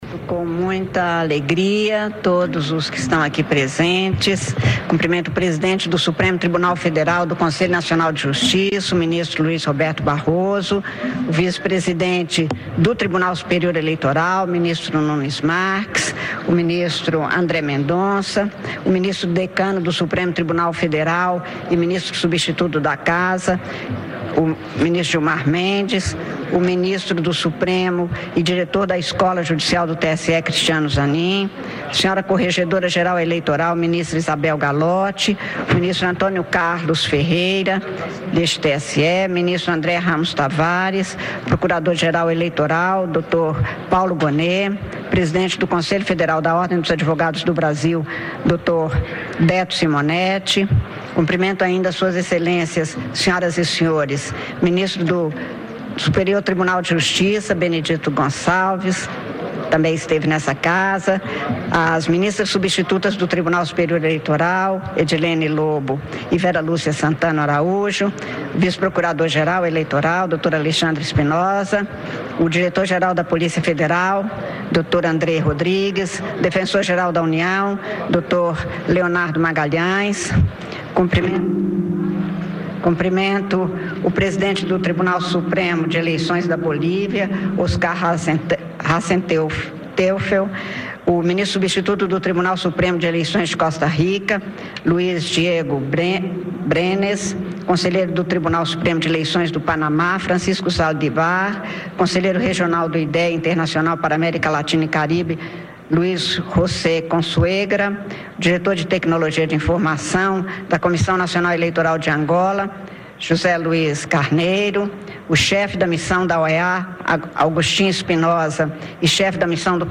A presidente do Tribunal Superior Eleitoral (TSE), ministra Cármen Lúcia, fez um balanço positivo do primeiro turno das eleições municipais, que aconteceram neste domingo (6). Ela também falou que eventuais irregularidades, como as denúncias de compra de votos, será apuradas com rigor pela Justiça Eleitoral. Confira a íntegra da entrevista coletiva, concedida na sede do TSE, na noite deste domingo.